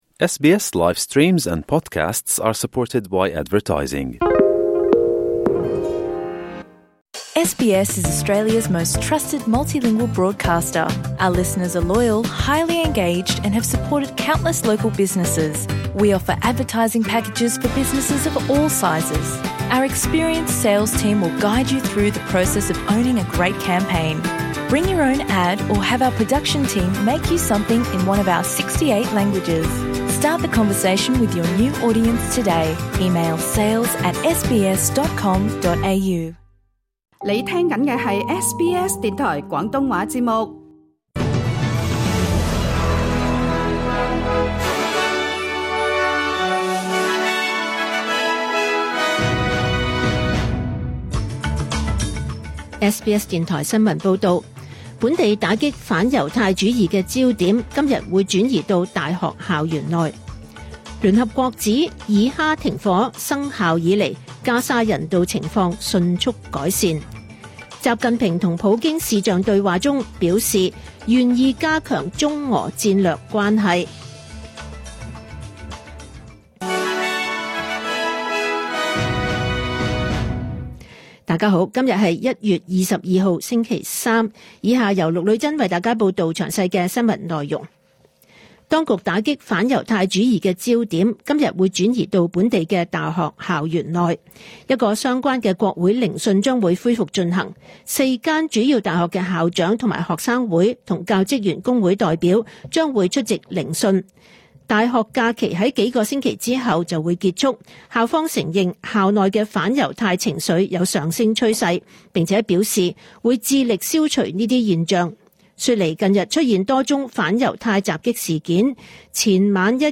2025 年 1 月 22 日 SBS 廣東話節目詳盡早晨新聞報道。